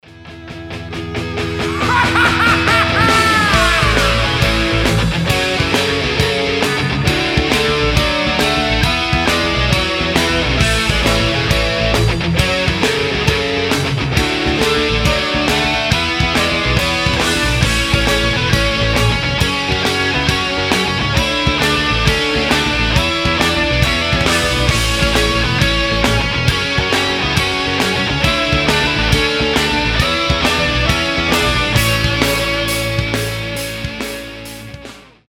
рок , инструментальные
смех , панк-рок , без слов